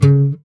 Index of /90_sSampleCDs/Sound & Vision - Gigapack I CD 2 (Roland)/GUI_ACOUST. 32MB/GUI_Acoust. Slap